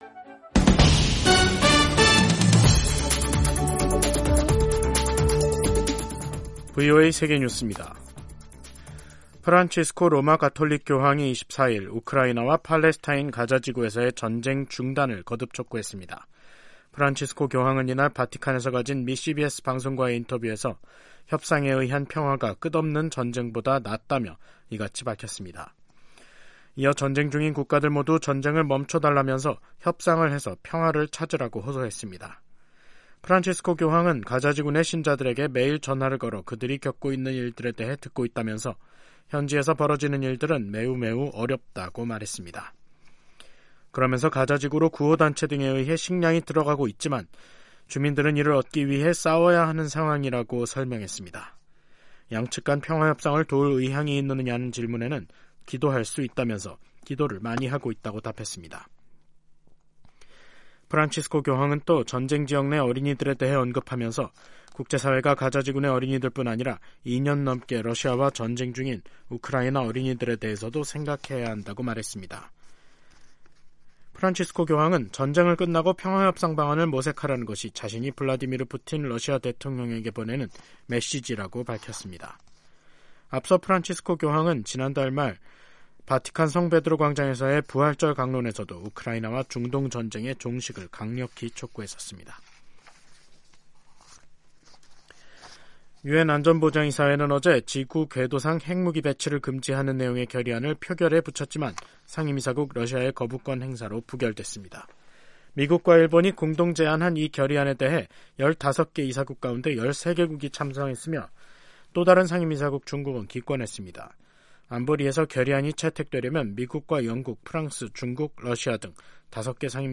세계 뉴스와 함께 미국의 모든 것을 소개하는 '생방송 여기는 워싱턴입니다', 2024년 4월 25일 저녁 방송입니다. '지구촌 오늘'에서는 미국이 우크라이나에 장거리 지대지 미사일인 에이태큼스를 인도한 소식 전해드리고, '아메리카 나우'에서는 미 연방대법원에서 아이다호주의 엄격한 낙태 금지법을 둘러싼 공방이 시작된 이야기 살펴보겠습니다.